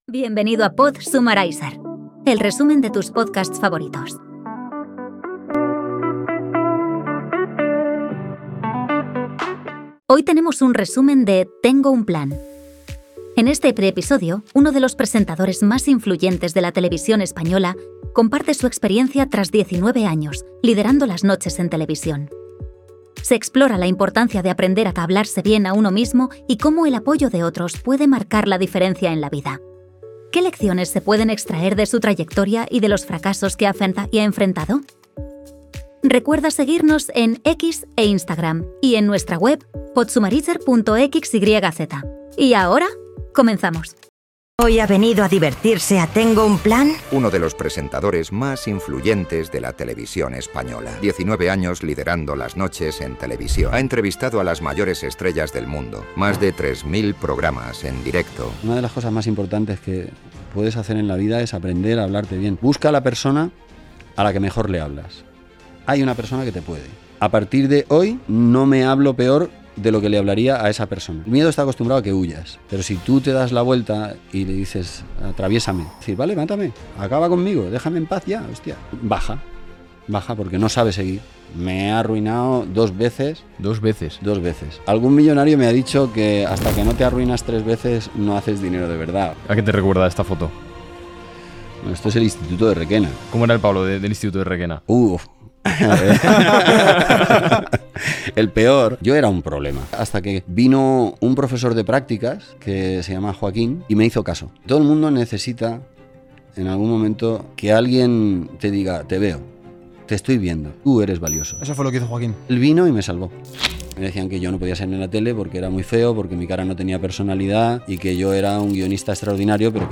Una conversación inspiradora que invita a la autoexploración y el crecimiento personal.